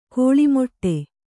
♪ kōḷi moṭṭe